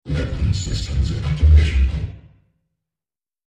These were made using the vocoder in FL studio, and edited again in Audacity.
A bit fuzzy, but they can be very useful for campaign-mods.